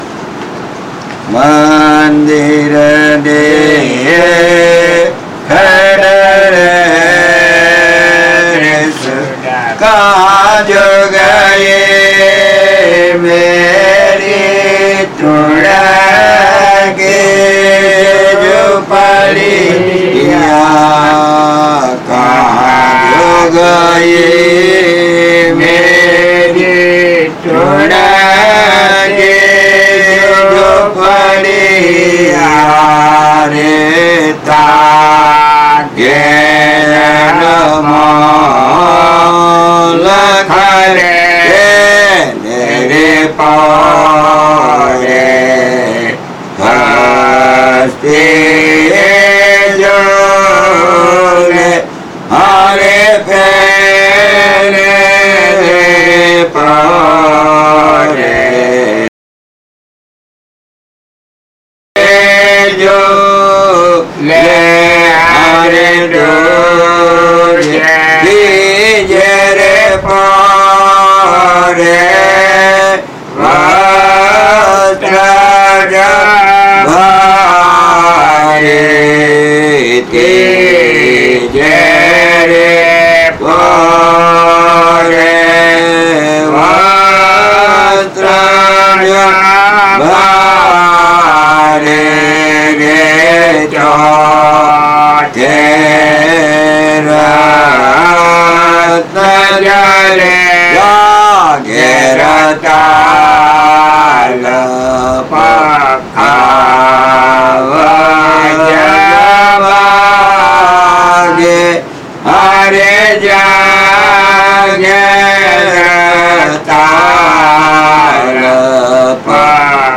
Kirtan - Dhaman (2006)